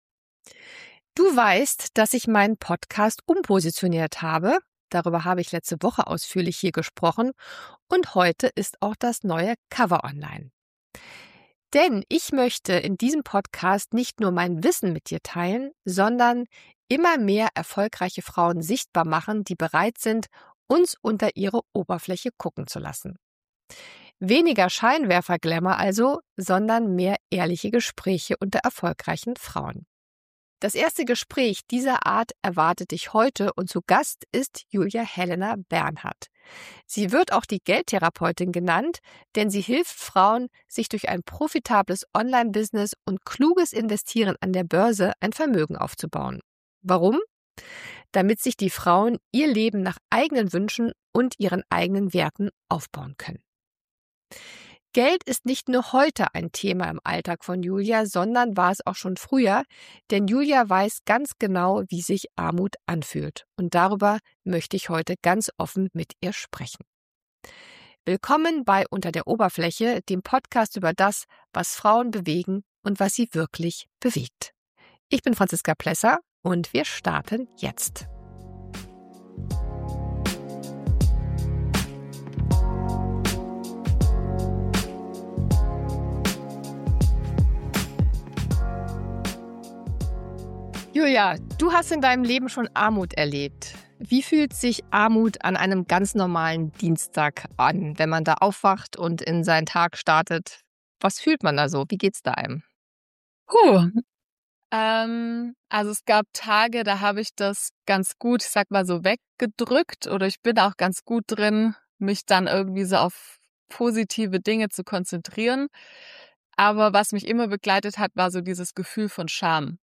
Dieses Gespräch ist eine Einladung an alle Frauen, die ihre finanzielle Verantwortung nicht länger abgeben wollen.